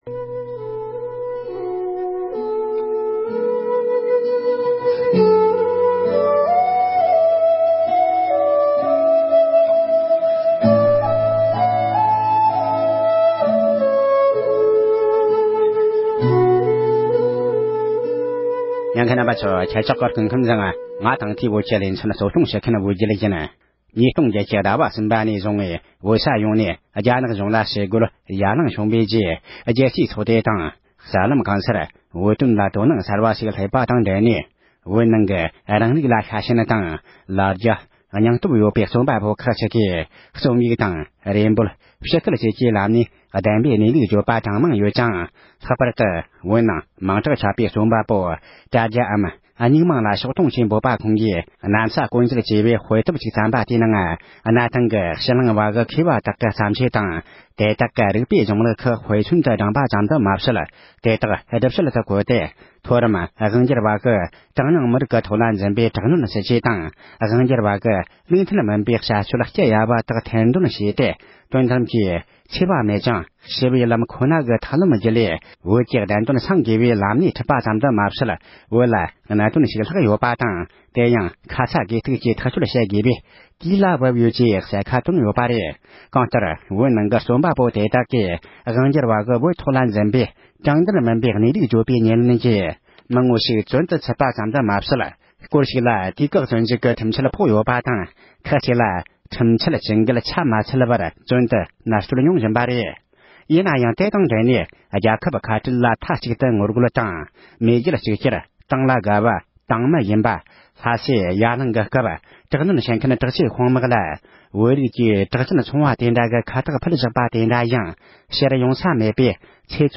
རྒྱ་མིས་བོད་ནང་༢༠༠༨ལོར་ཞི་ངོ་རྒོལ་བྱུང་བའི་རྗེས་བོད་མི་རྩོམ་པ་པོ་དེ་དག་ལ་དམ་བསྒྲཊ་དང་འཛིན་བཟུང་བྱེད་བཞིན་པའི་ཐད་གླེང་མོལ།